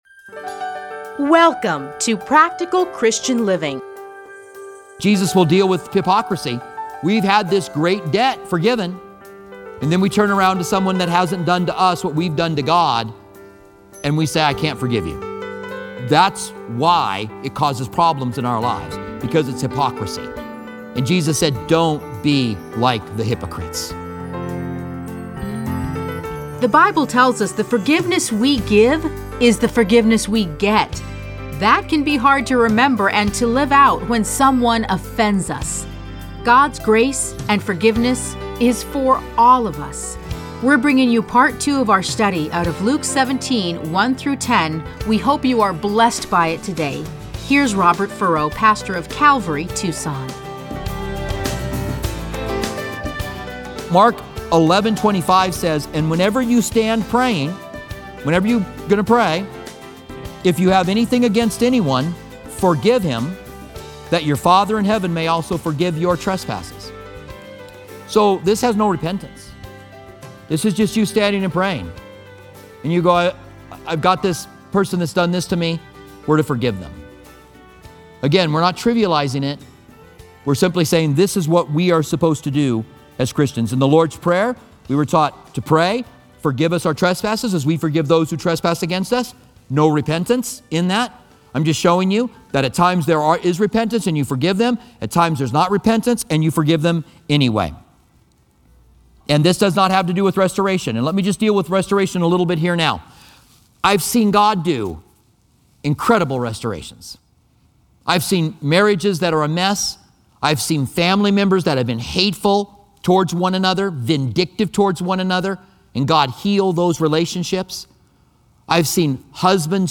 Listen to a teaching from Luke 17:1-10.